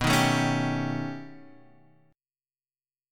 B+9 Chord
Listen to B+9 strummed